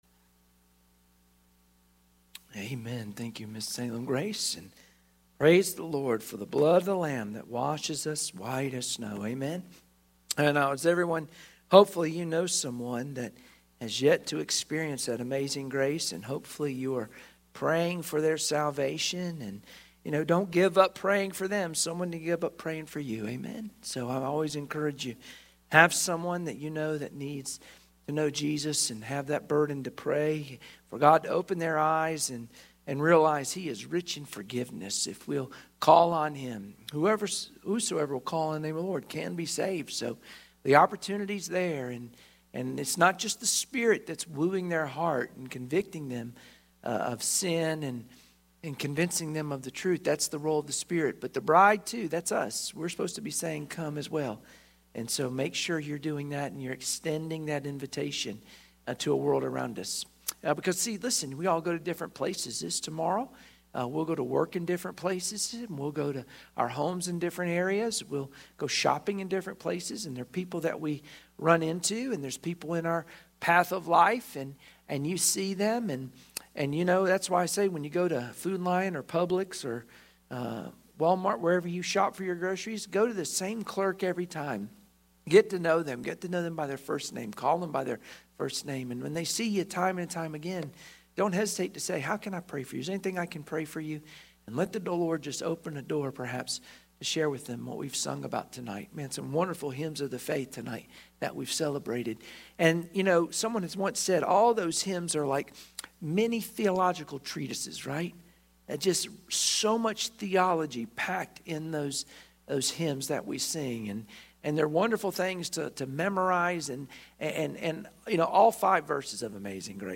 Luke 7:36-50 Service Type: Sunday Evening Worship Share this